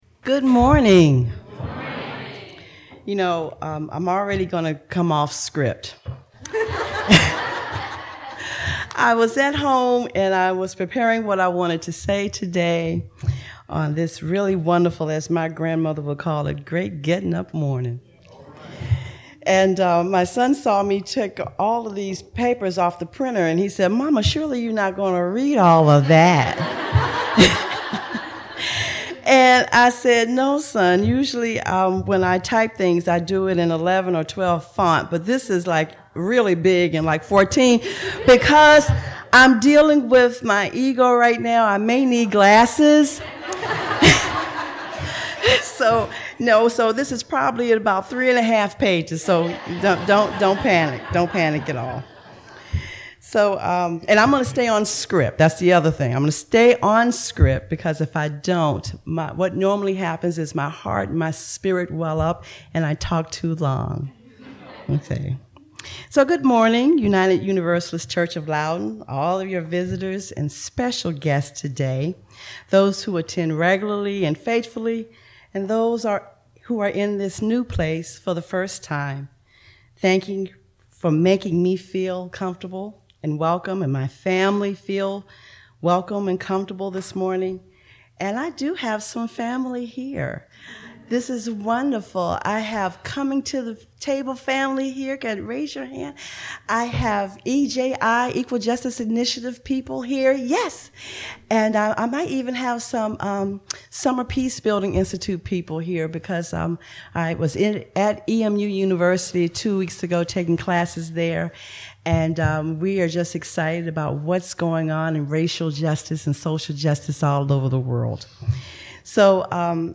In this heartfelt sermon, a speaker for Coming to the Table RVA explores the profound meaning of reparations by defining it as the active process of repairing systemic and personal brokenness.